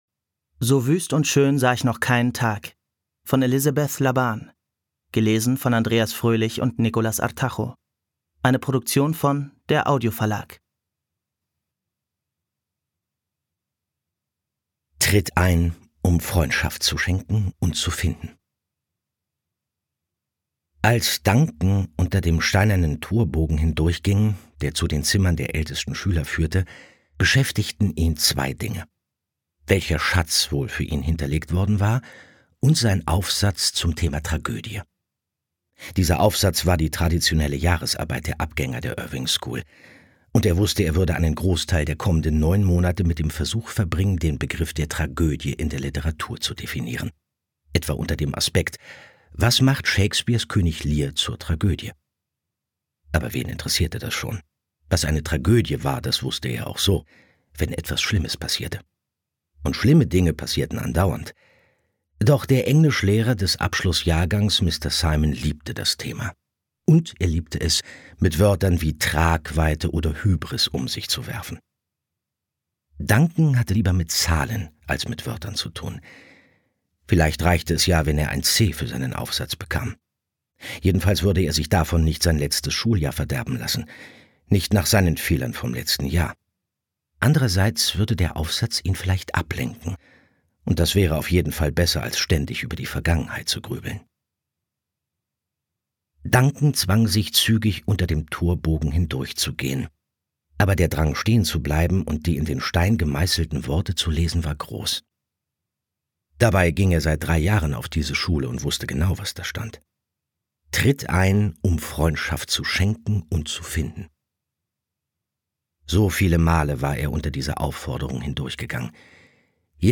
Andreas Fröhlich, geboren 1965, spricht Bob Andrews in der Kultserie »Die drei ???«.